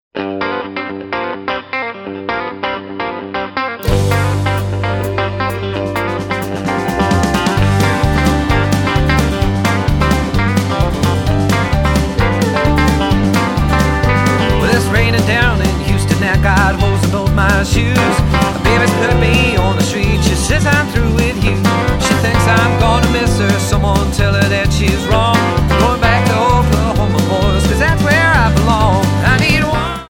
--> MP3 Demo abspielen...
Tonart:G Multifile (kein Sofortdownload.